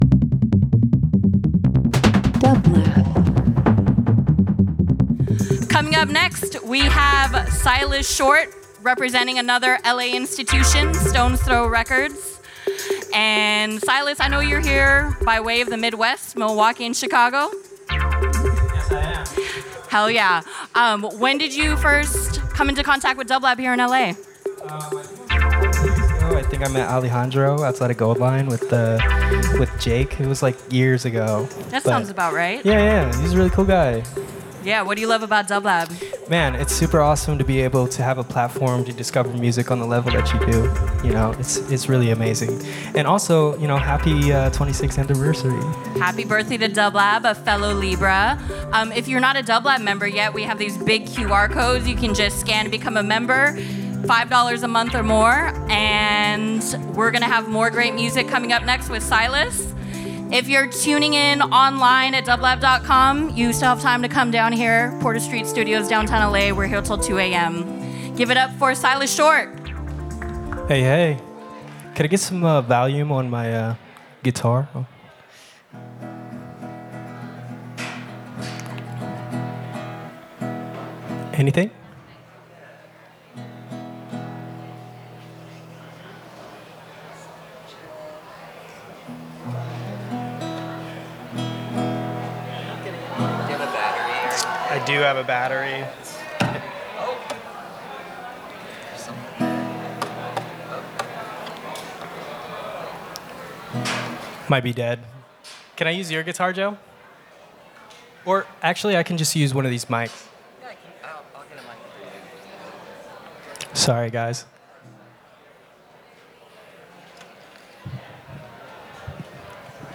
LIVE FROM THE LOFT @ PORTER STREET STUDIO
Alternative Live Performance Pop Soul